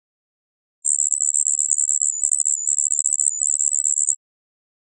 Listen_Carefully ID: 0x09 // STATUS: ACTIVE Kami menyadap file audio yang aneh ini. Terdengar seperti white noise atau spektrum yang rusak.